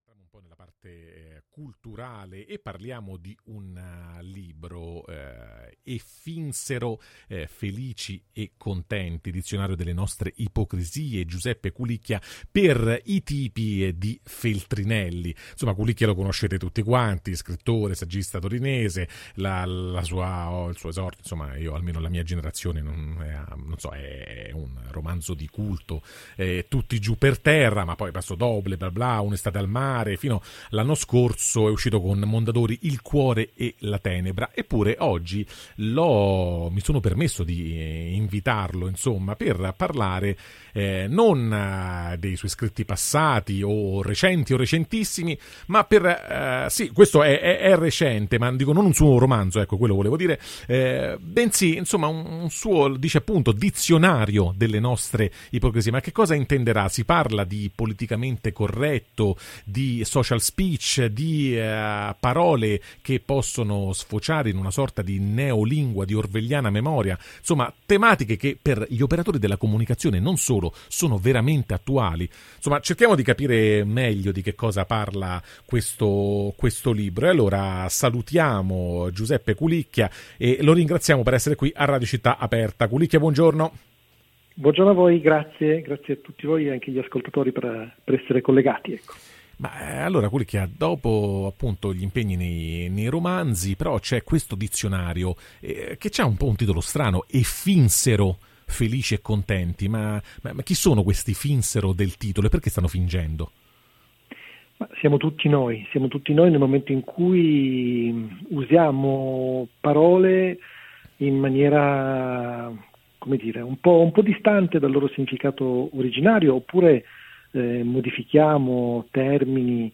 Il dizionario delle parole proibite: quando non offendere nessuno diventa l’arte degli ipocriti [Intervista a Giuseppe Culicchia] | Radio Città Aperta